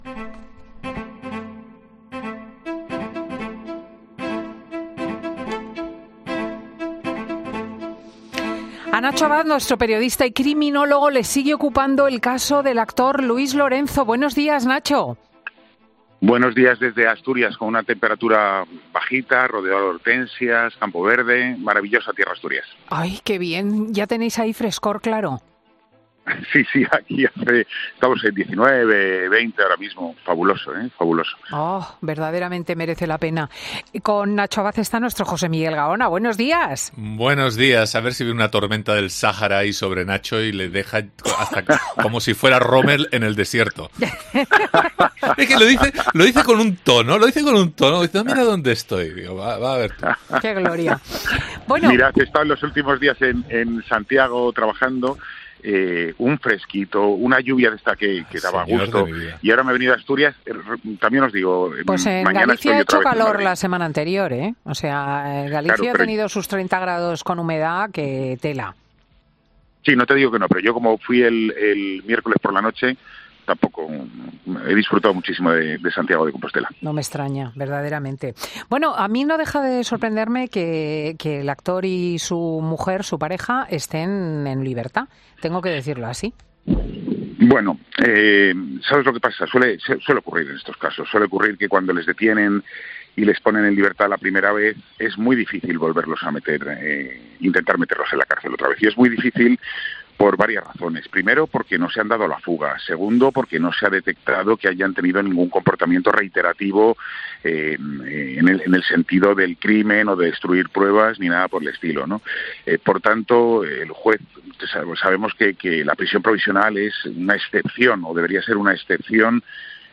AUDIO: El periodista especializado en sucesos cuenta en Fin de Semana con Cristina las novedades sobre el mediático caso del actor, su mujer y la tía...